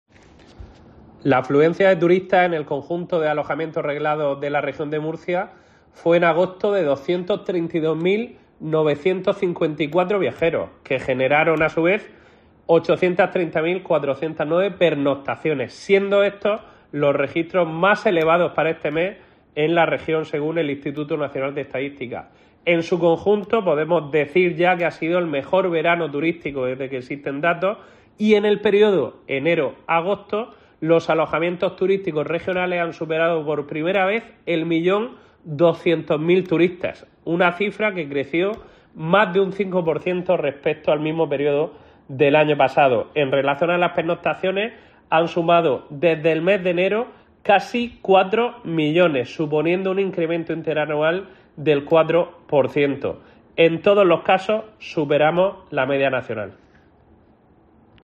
Juan Francisco Martínez, director general del ITREM